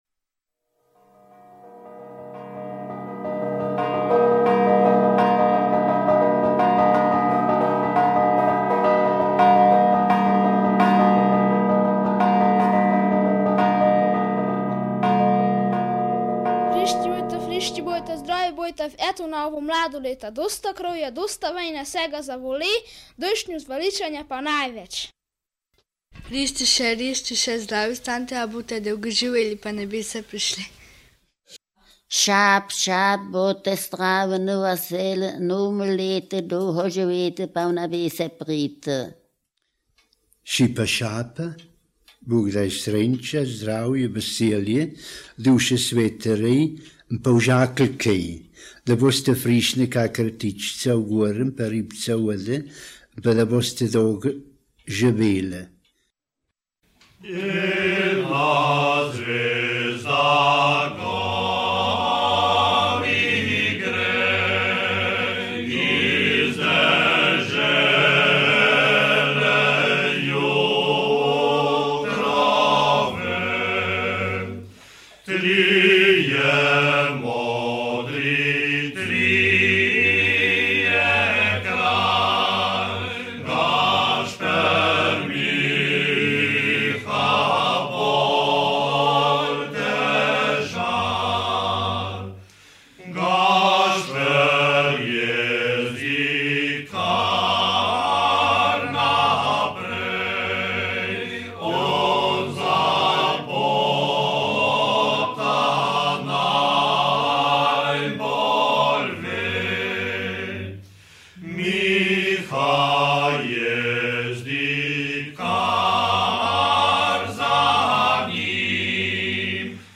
kolednisko_voscilo.mp3